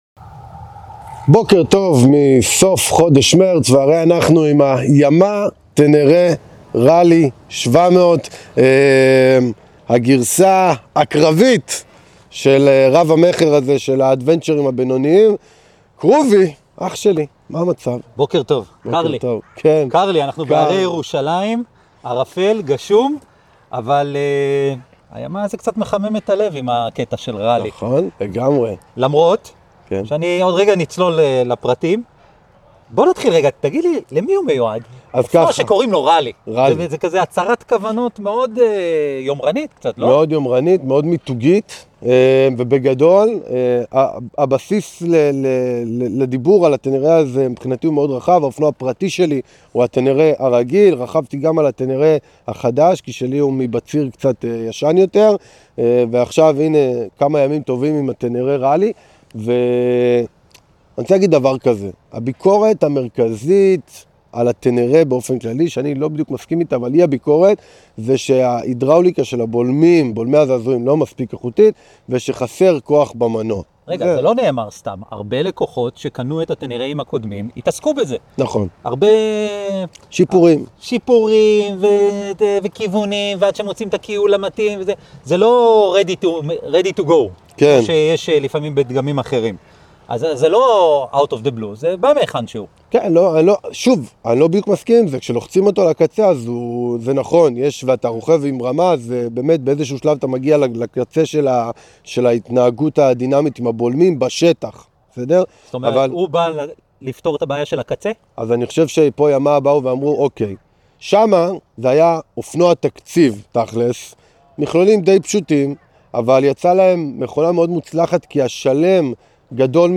ביום גשום, חורפי ובערפל הרי ירושלים, עצרנו לשיחה על האופנוע.